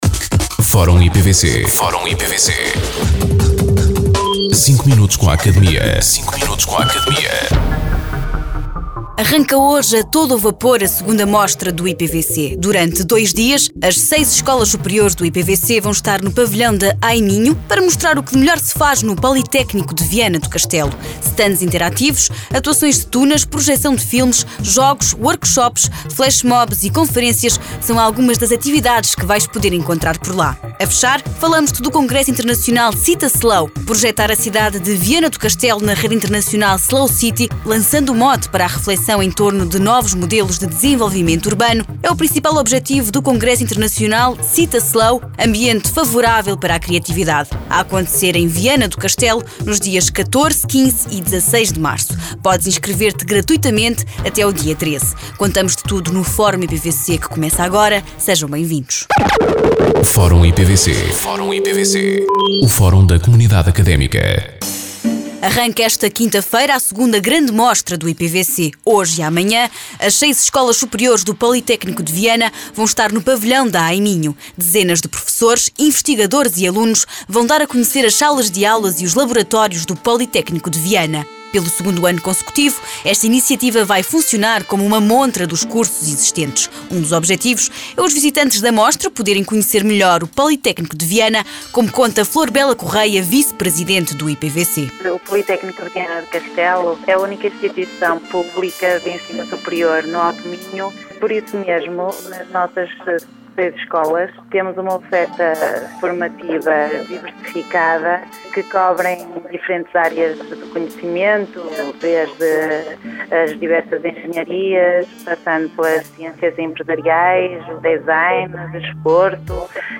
Entrevistados: